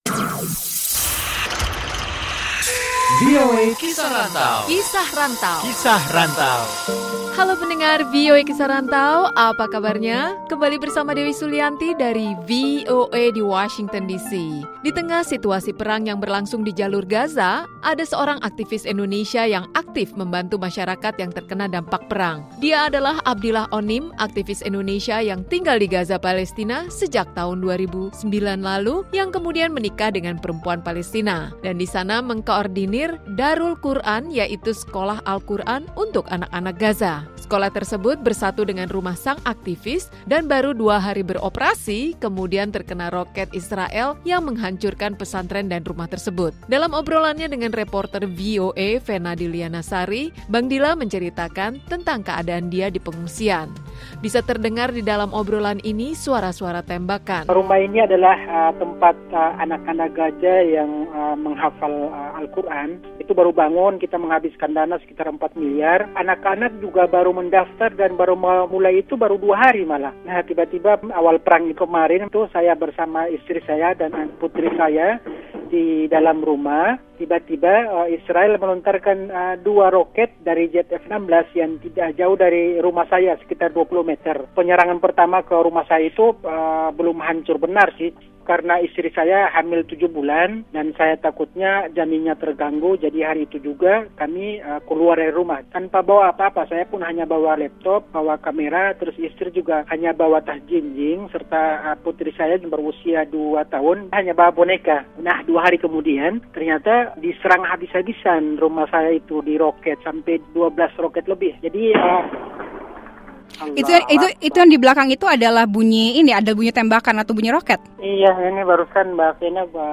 Dalam wawancara ini terdengar suara-suara tembakan/bom.